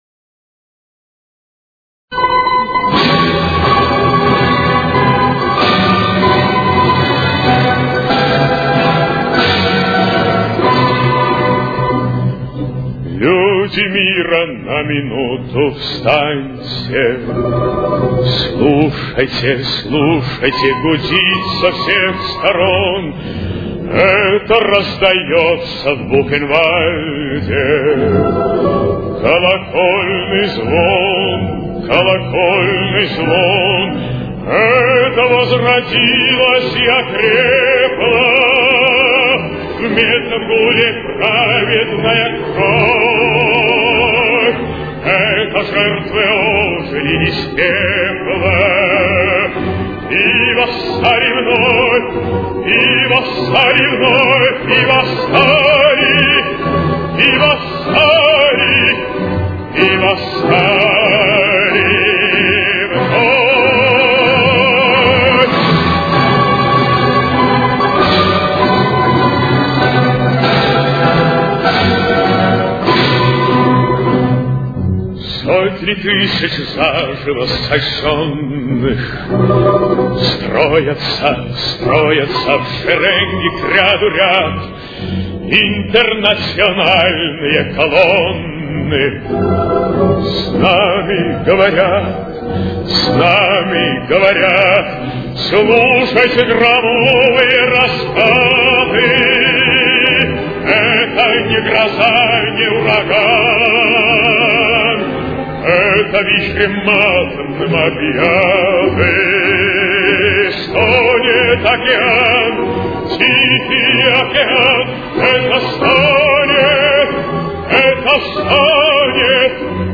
очень низким качеством (16 – 32 кБит/с)